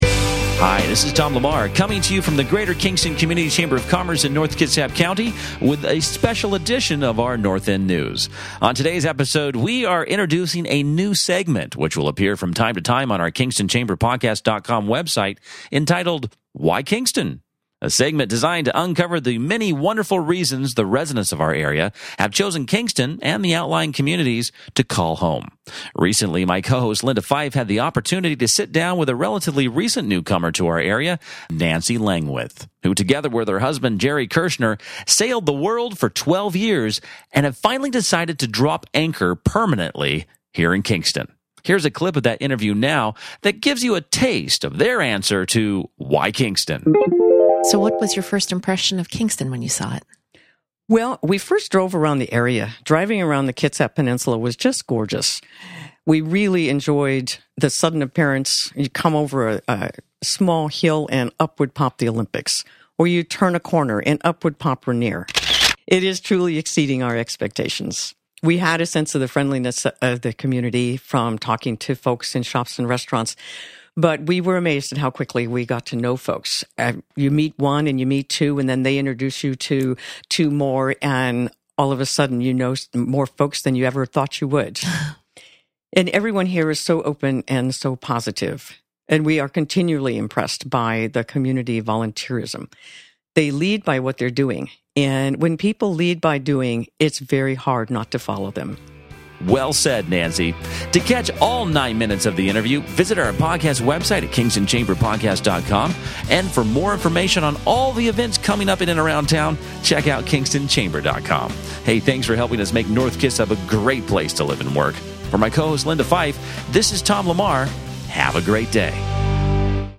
(radio spot)